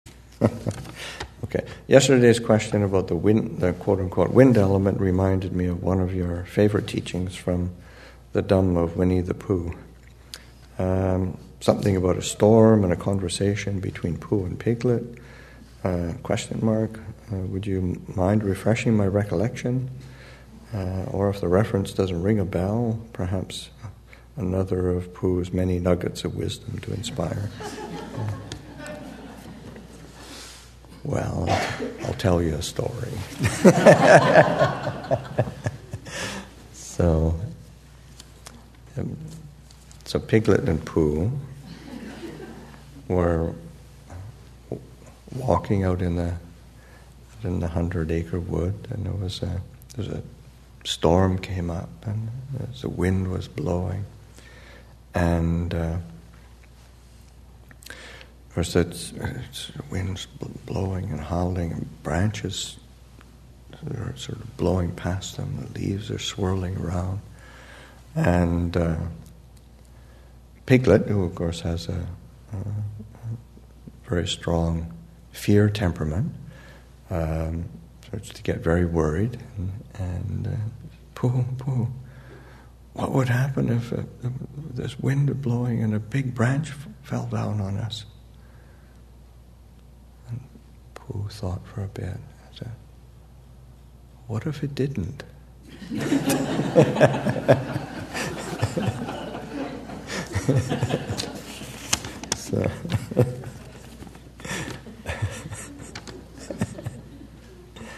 2015 Thanksgiving Monastic Retreat, Session 5 – Nov. 25, 2015